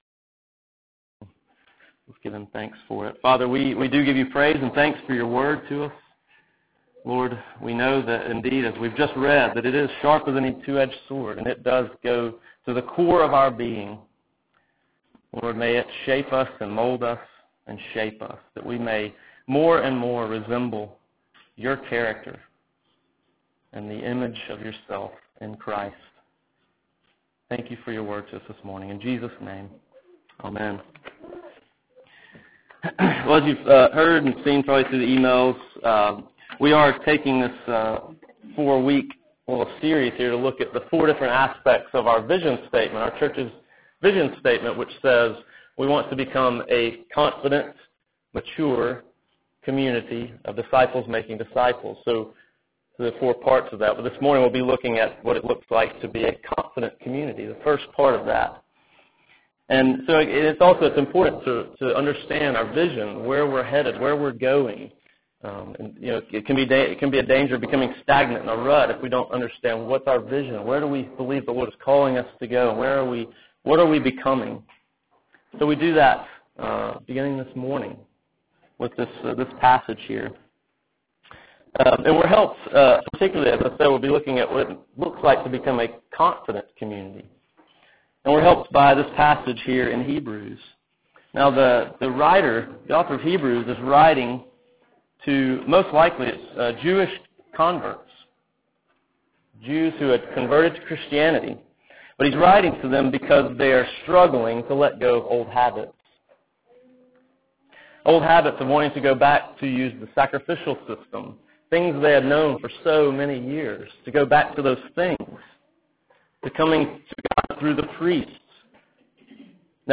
Confidence Posted in CCC Vision Statement ← Newer Sermon Older Sermon →